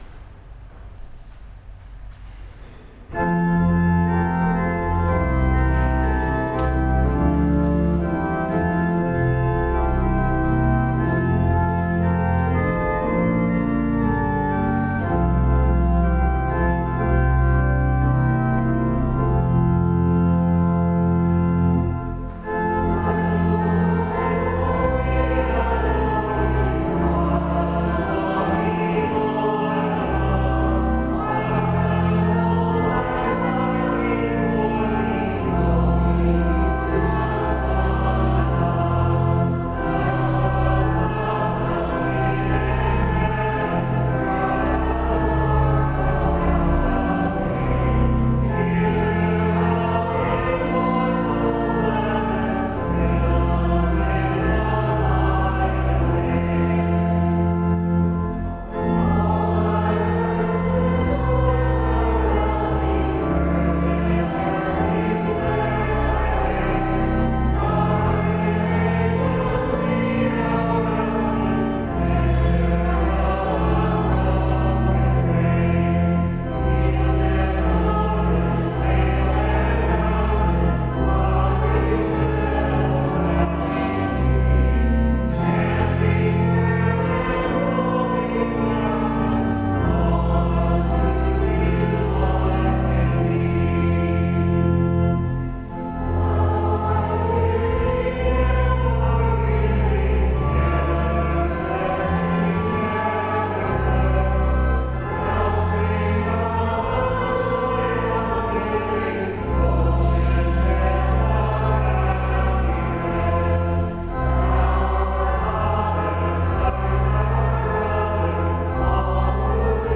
St. Boniface Catholic Church
Organ by Edmund Giesecke of Evansville, Indiana
The oak console organ was purchased. It has 10 ranks, and 535 pipes and the cost was $950.00.
Evansville AGO Historic Organ Concert, November 2014